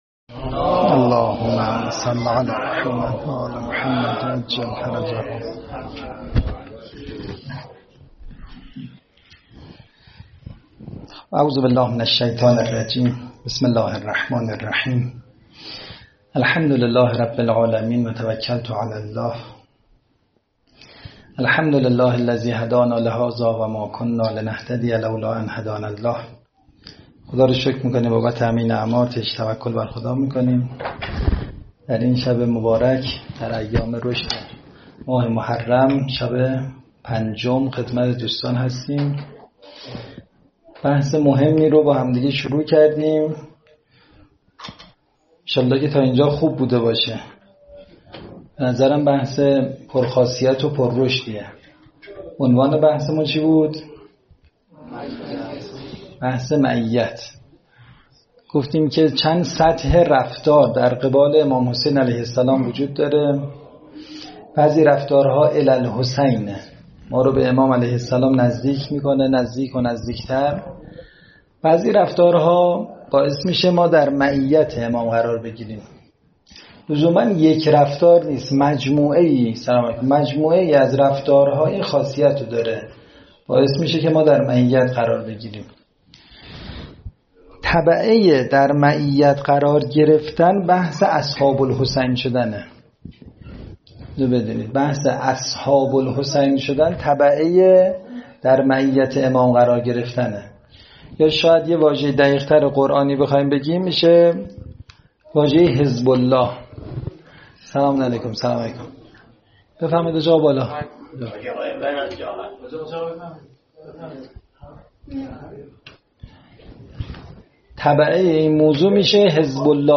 فایل صوتی سخنرانی